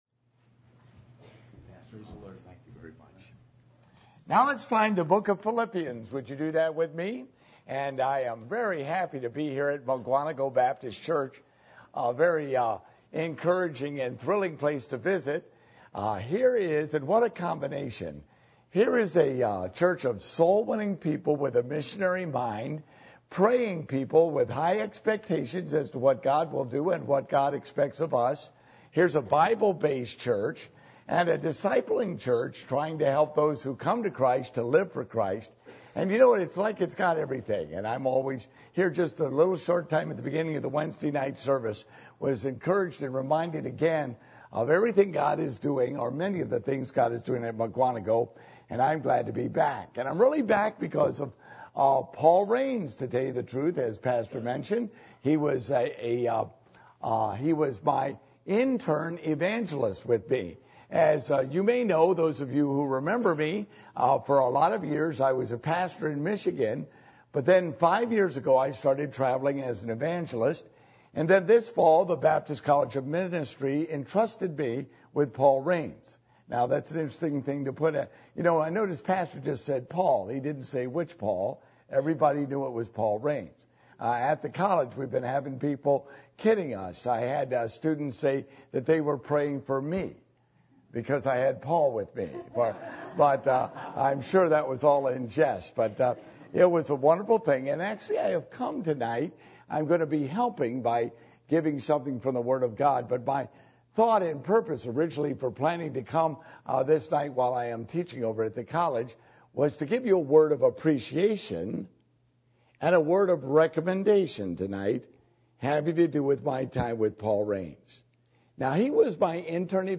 Passage: Philippians 2:17-24 Service Type: Midweek Meeting